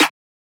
Claps